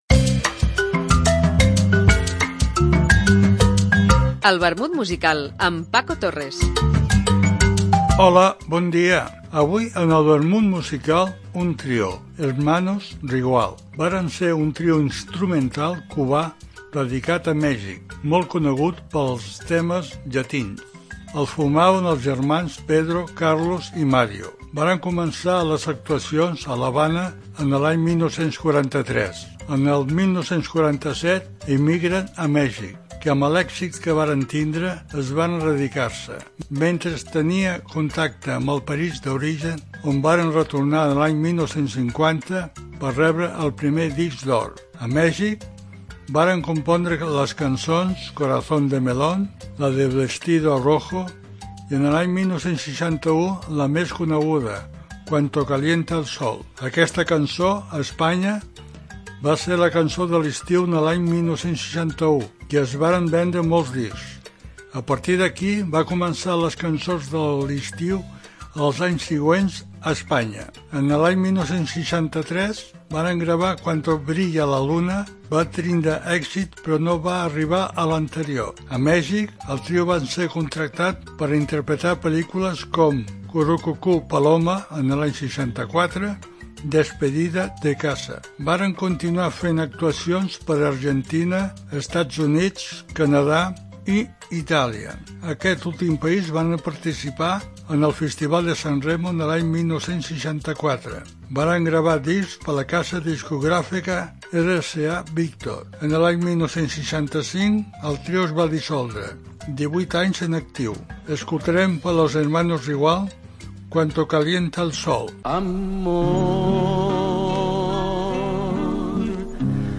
trios...etc. Una apunts biogràfics acompanyats per una cançó.